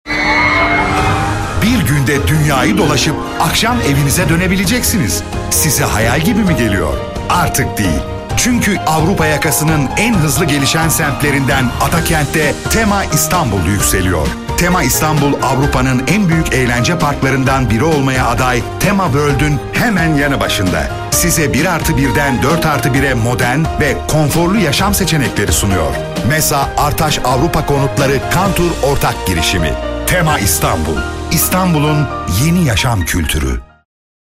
TEMA-REKLAM-SESI.mp3